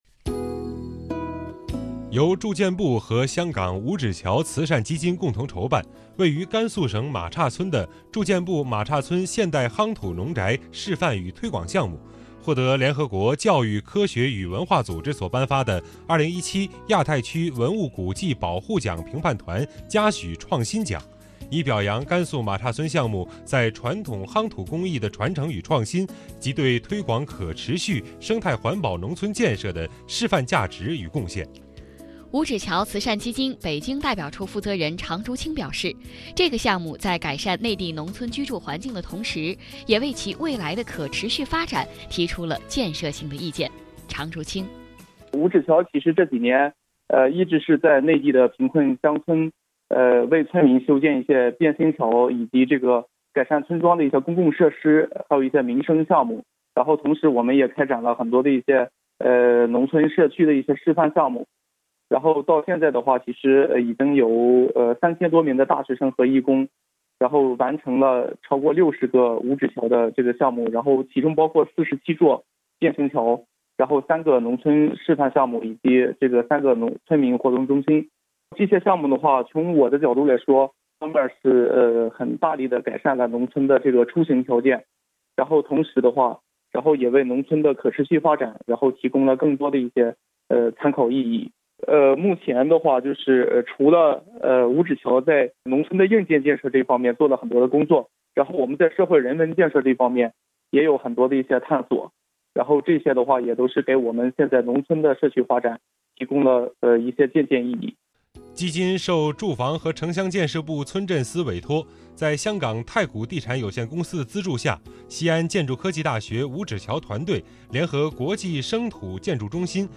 随着两地交流日益扩大，驻京办加强了在华北地区的宣传和推广工作，并自2006年起与中央人民广播电台「华夏之声」（2019年9月起更名为中央广播电视总台大湾区之声）携手打造普通话广播节目「每周听香港」，在华北九个省、市、自治区级电台播出，以趣味与信息并重的形式，把香港的最新发展带给当地听众。